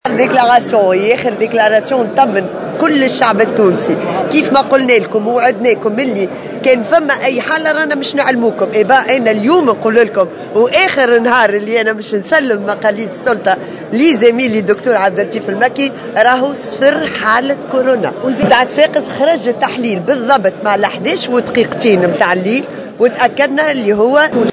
أكدت وزيرة الصحة المغادرة سنية بالشيخ في تصريح لمراسلة الجوهرة "اف ام" اليوم الجمعة قبيل موكب تسليم وتسلم السلطة بين الحكومة المغادرة و الحكومة الجديدة عدم تسجيل أية اصابة بالكورونا في تونس.